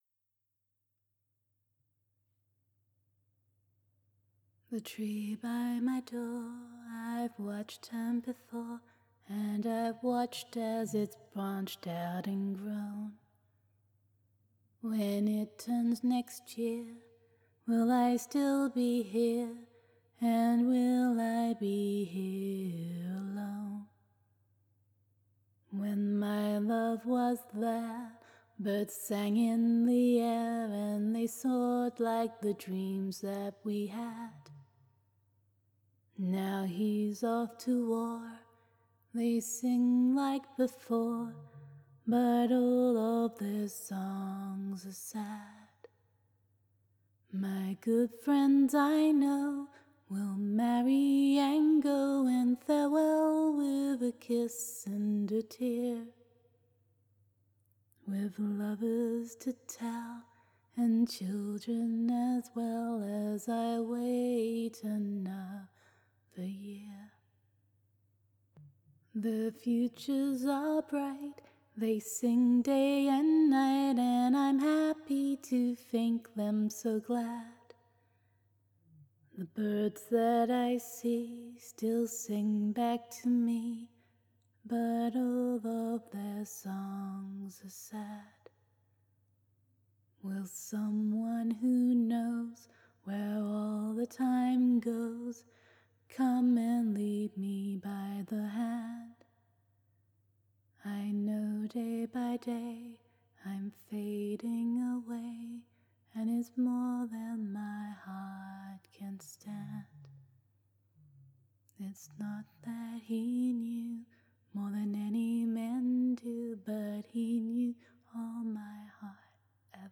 Still can't sing with added geekery!